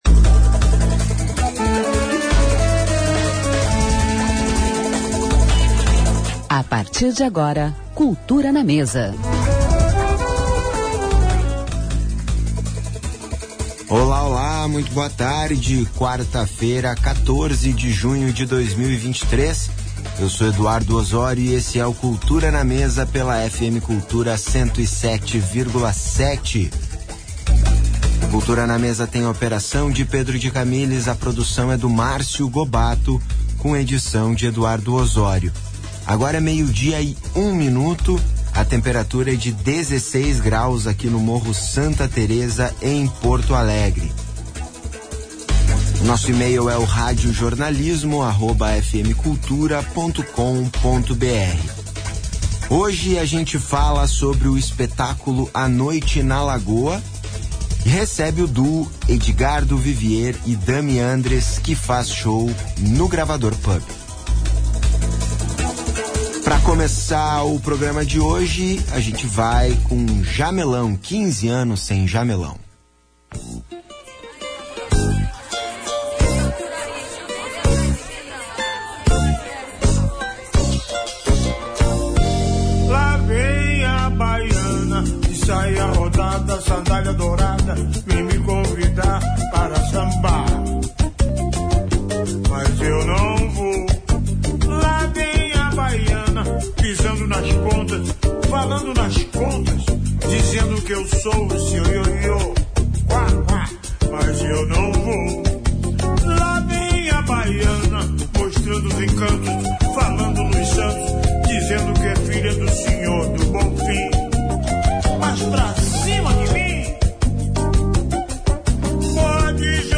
Entrevista
Entrevista e música ao vivo com o saxofonista